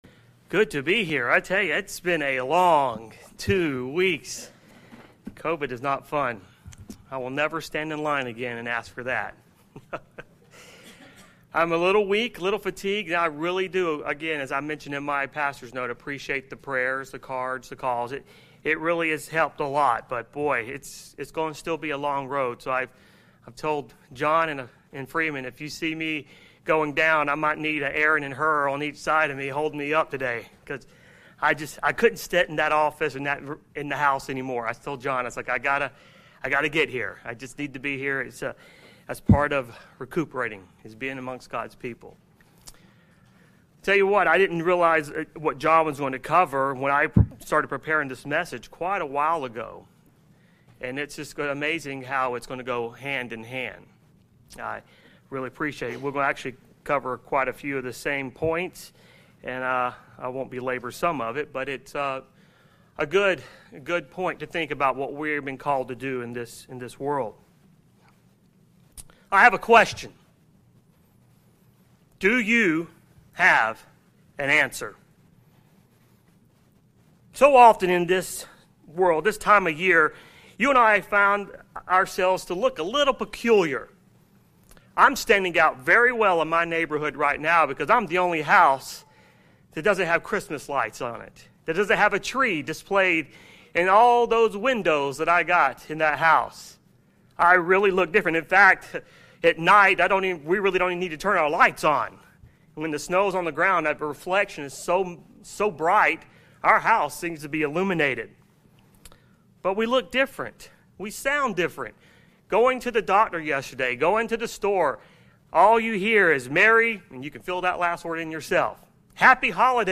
Are we equipped to respond with the truth in a way that exemplifies Christ? Revealing God’s truth in a loving but compelling way is a responsibility that we are expected to fulfill. This sermon provides some tools that can help us walk this out.